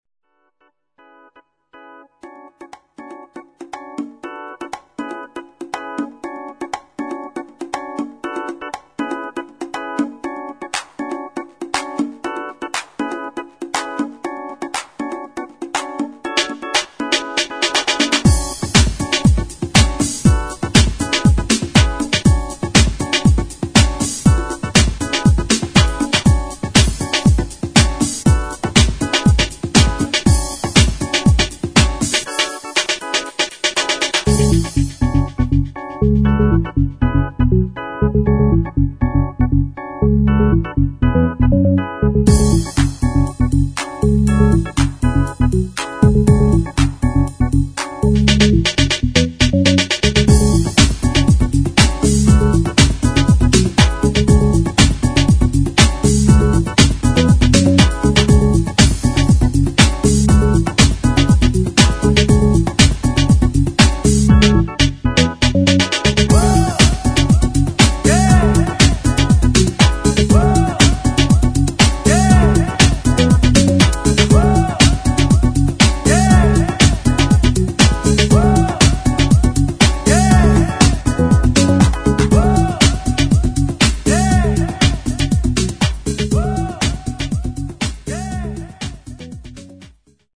[ HOUSE / BREAKBEAT ]